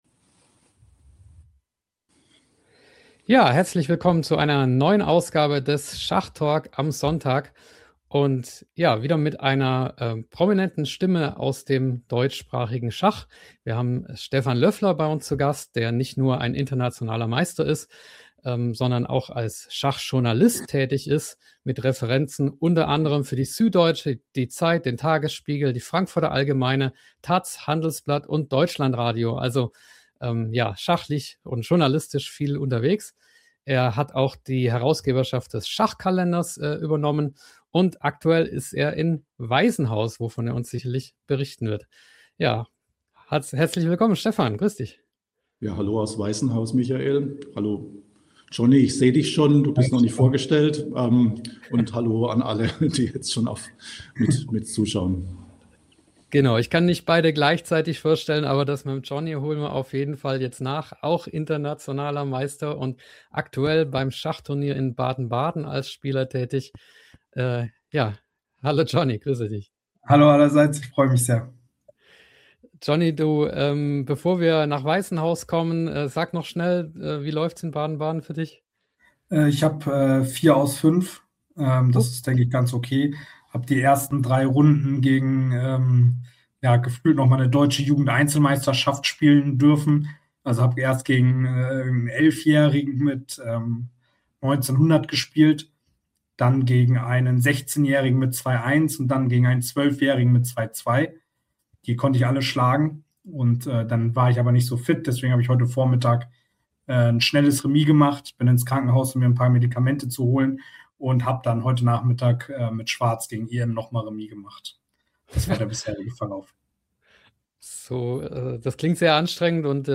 Live aus Weissenhaus